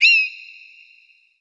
Passaros
jastrzab02.wav